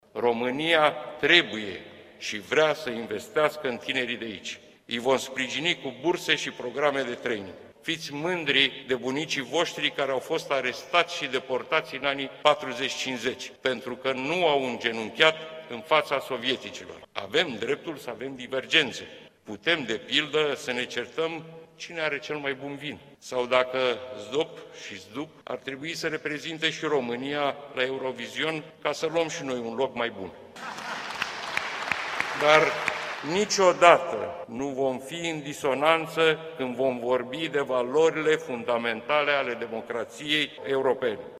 Parlamentele României și Republicii Moldova s-au întrunit astăzi, în premieră, într-o ședință comună care a avut loc la Chișinău.
Legătura specială dintre România și Republica Moldova a fost amintită și de președintele Camerei Deputaților de la București, Marcel Ciolacu.